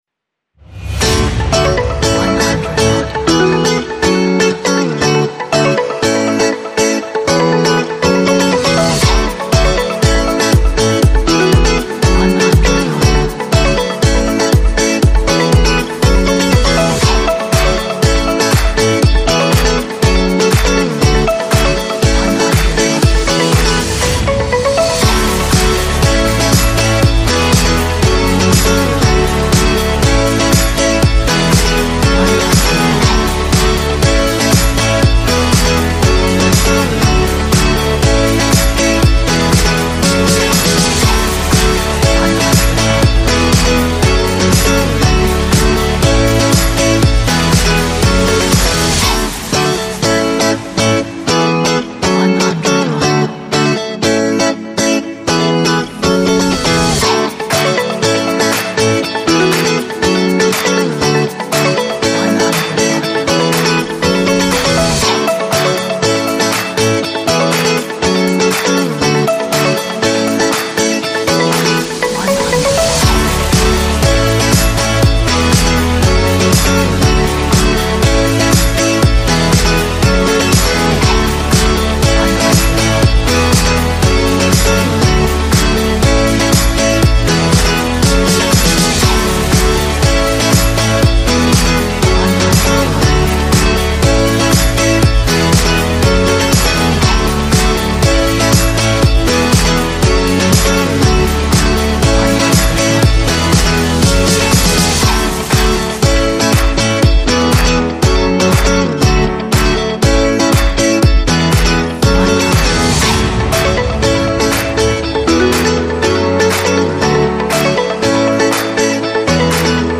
这是一首鼓舞人心和励志的背景音乐，充满活力和快乐的心情。